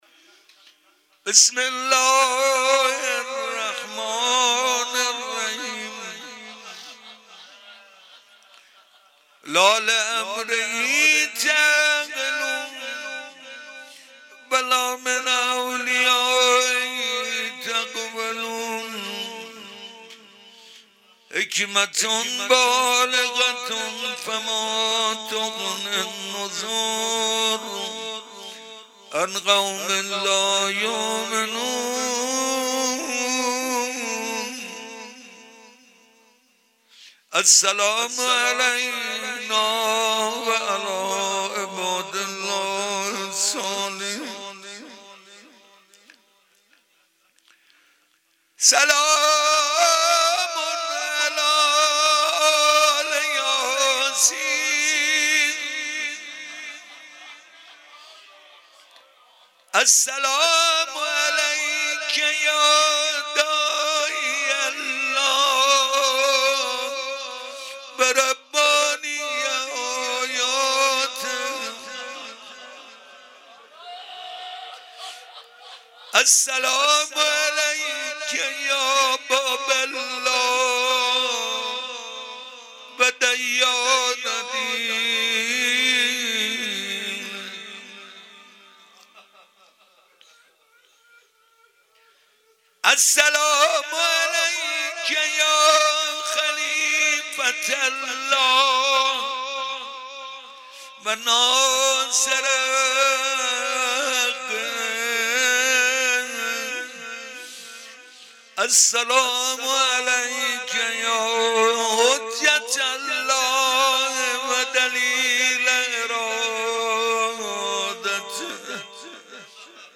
روضه تخریب بقیع
70886روضه-تخریب-بقیع.mp3